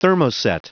Prononciation du mot thermoset en anglais (fichier audio)
Prononciation du mot : thermoset